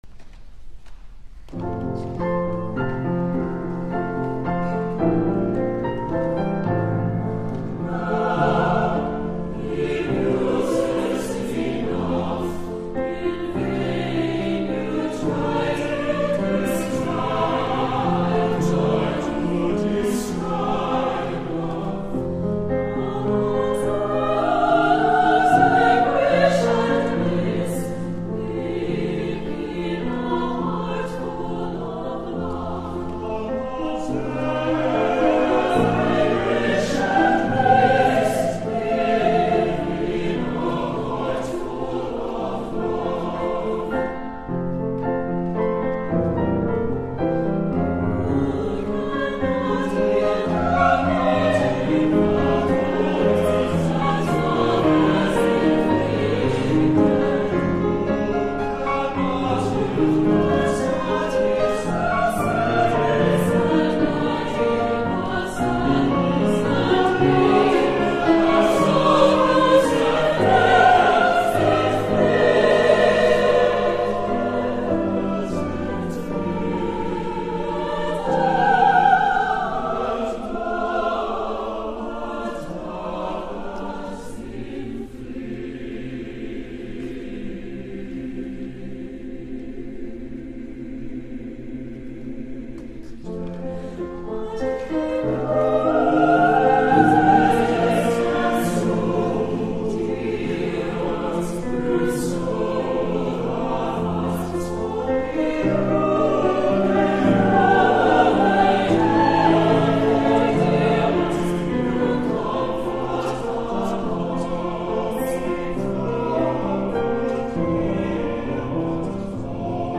Accompaniment:      With Piano
Music Category:      Choral
Performed at the Choral Arts Symposium